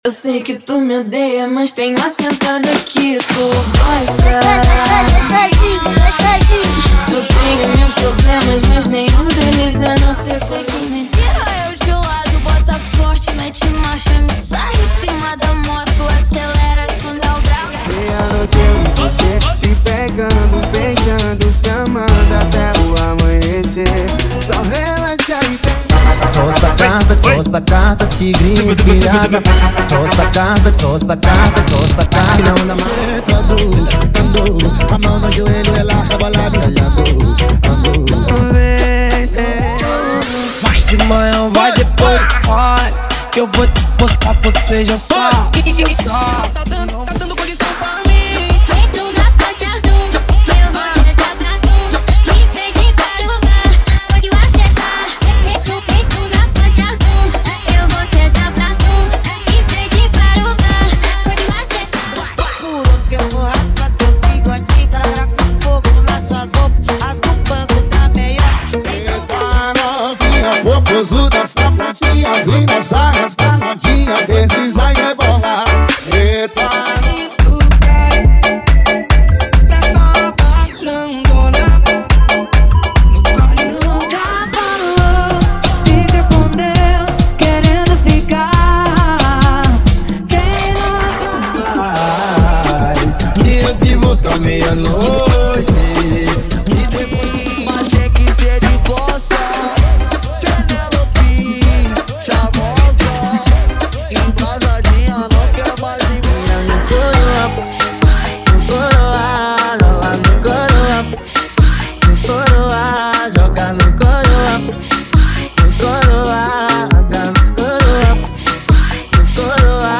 FUNK RAVE
FUNK REMIX
FUNK RJ
FUNKNEJO
FUNK GRINGO
BREGA FUNK
FUNK TAMBORZÃO
AFRO FUNK
Sem Vinhetas